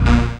37bz01syn-a#.wav